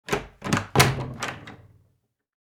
DoorOpen2.wav